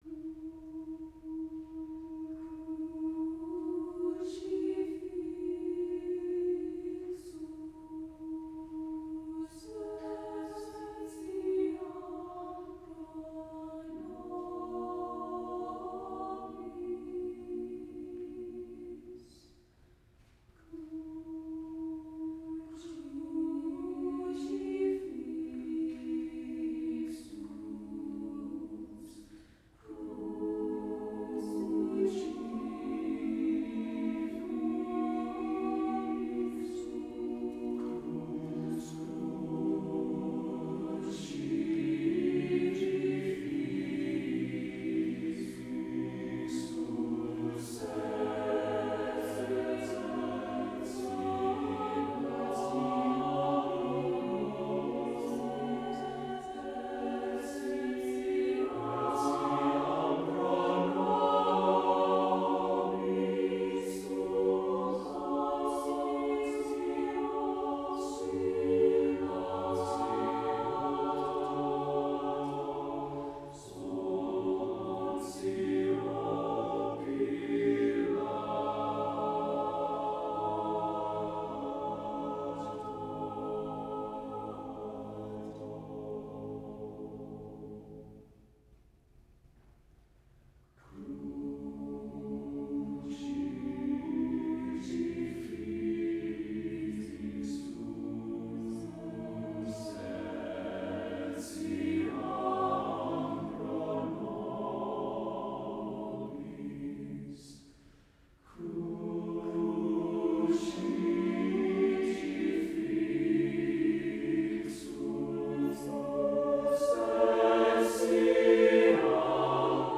Crucifixus – motet SATB divisi